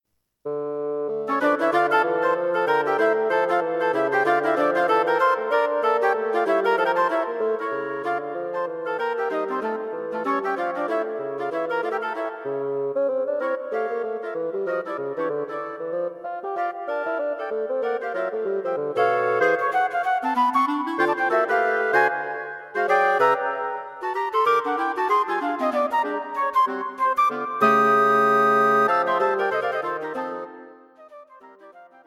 Wind Quartet
This modern Jazz piece has a hint of the exotic about it.